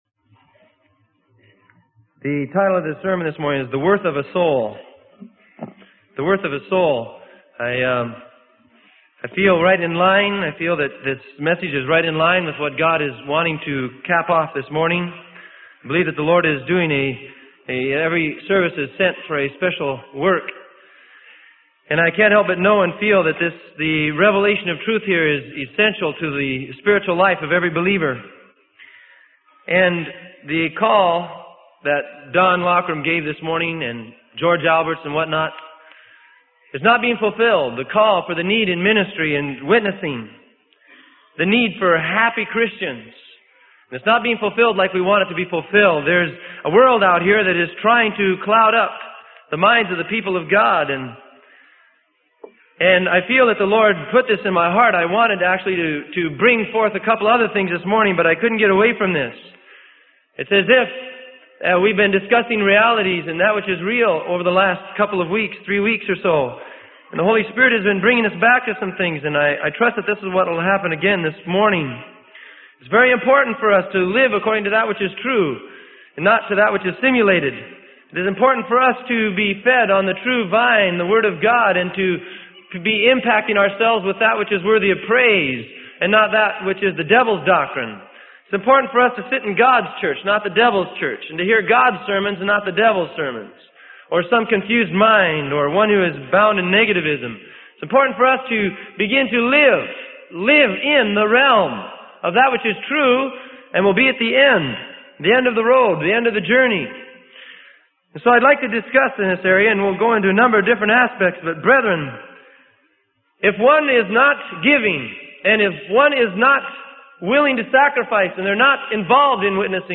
Sermon: The Worth of a Soul - Freely Given Online Library